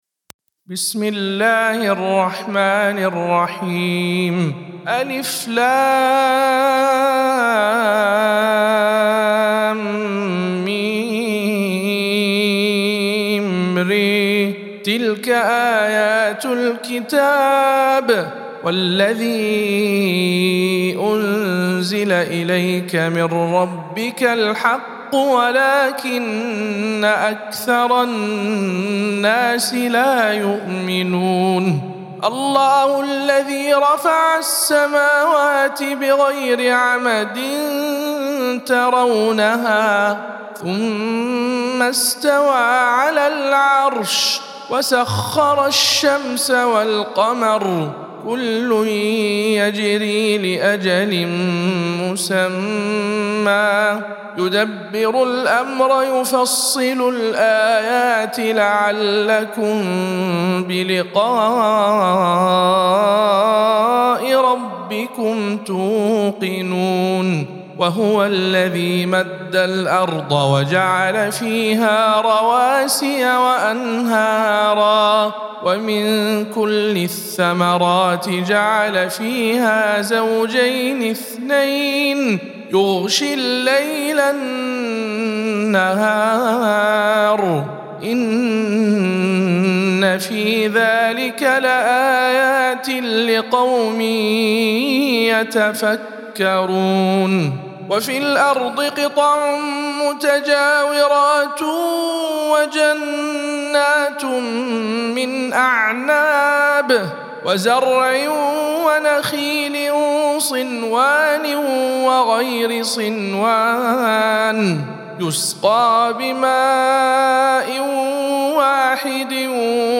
سورة الرعد _ رواية هشام عن ابن عامر